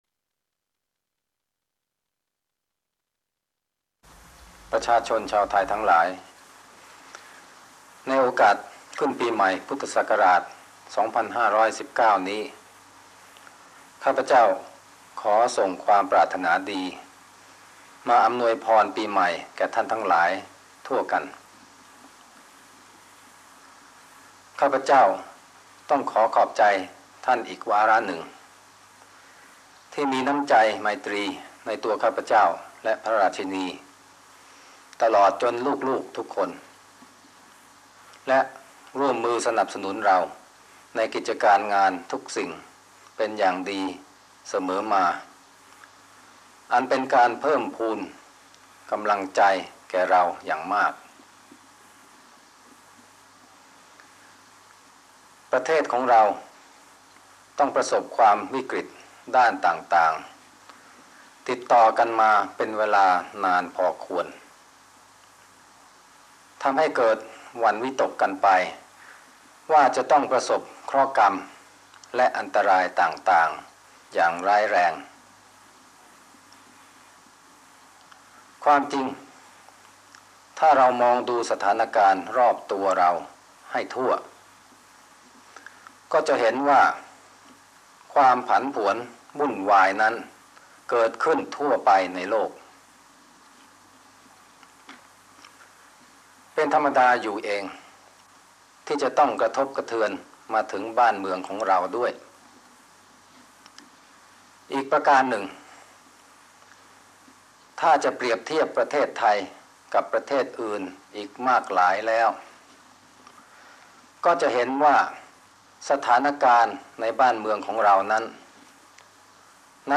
พระราชดำรัสพระบามสมเด็จพระเจ้าอยู่หัว ในวันขึ้นปีใหม่ 2519
ลักษณะของสื่อ :   คลิปการเรียนรู้, คลิปเสียง